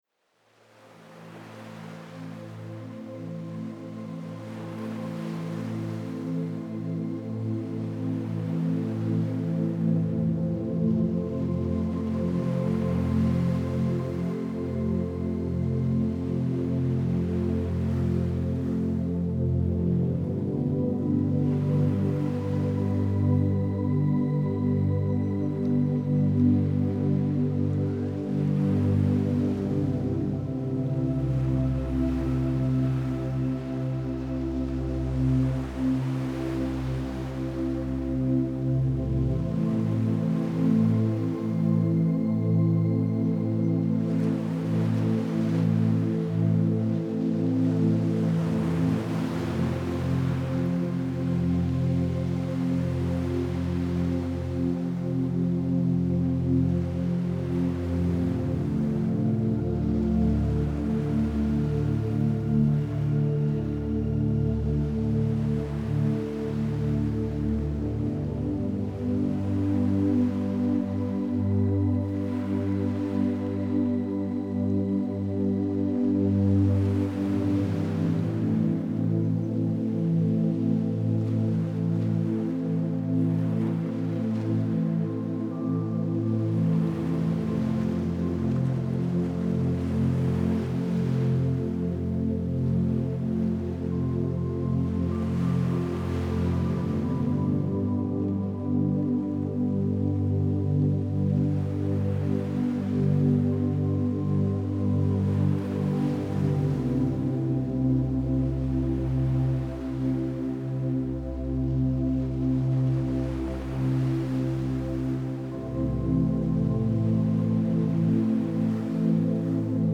это спокойная и мелодичная композиция в жанре неоклассики